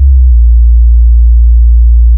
Subhuman Bass 65-10.wav